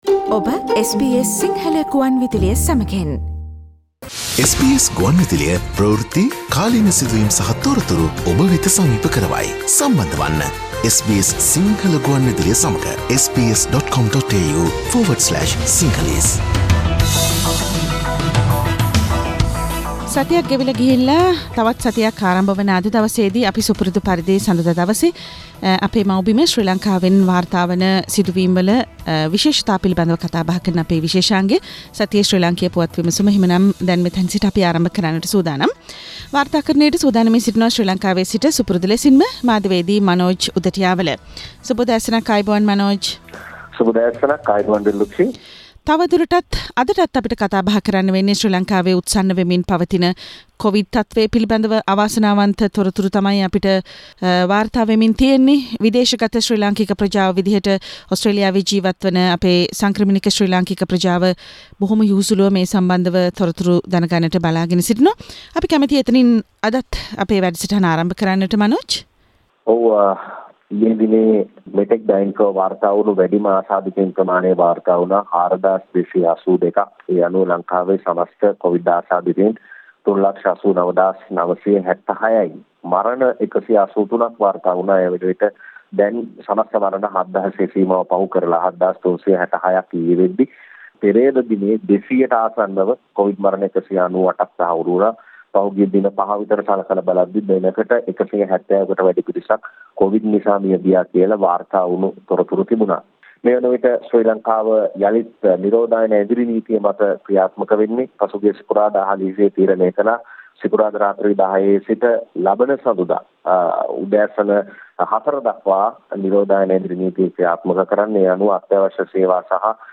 ලංකාවේ නිරෝධායන ඇඳිරි නීතිය අස්සේ රනිල් ගොථා ට ගෙනා කොවිඩ් මර්දන යෝජනා 21: ශ්‍රී ලාංකීය පුවත් විමසුම